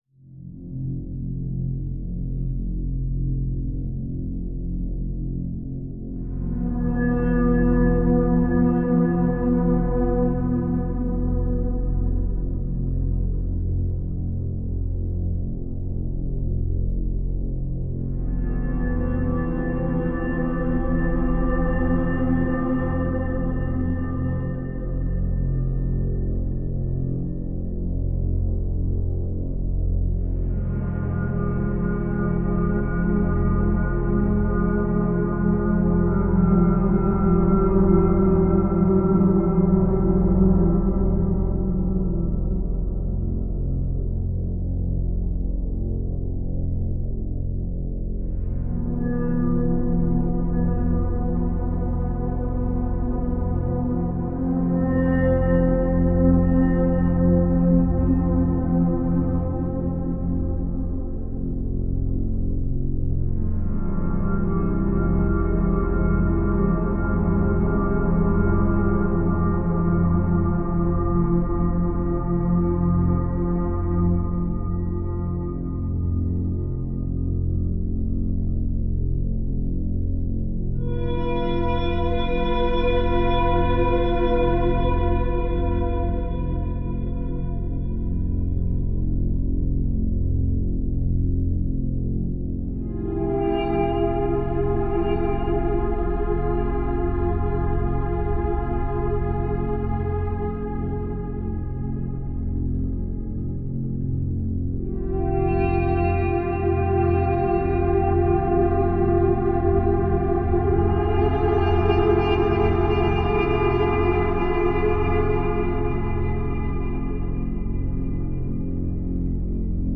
dark ambient tension abandoned places horror